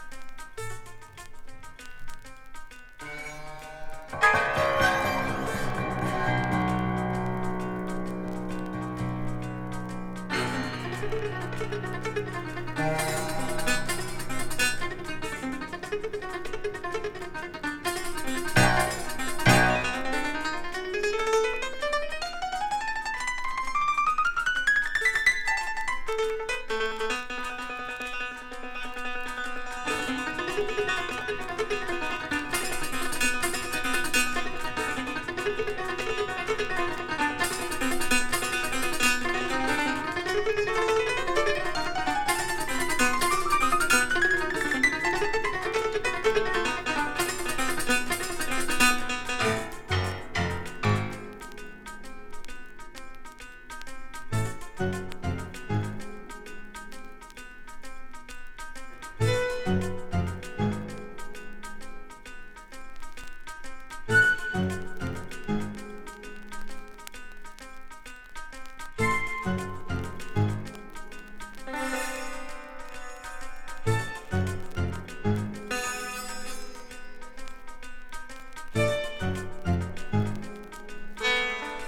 ドイツ産コンテンポラリー・エスノ・ジャズ！スリリングなモダンクラシカル曲〜シンセポップ調まで収録の作品。